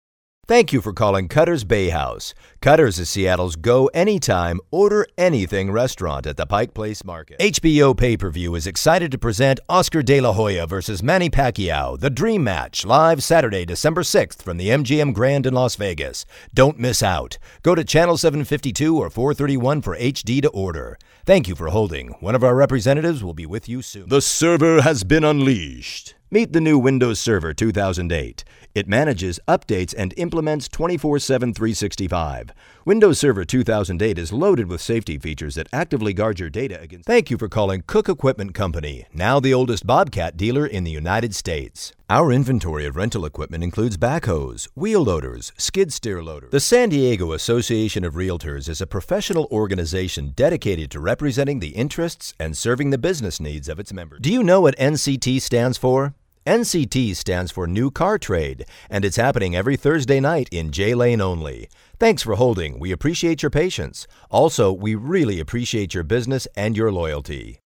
Voice Samples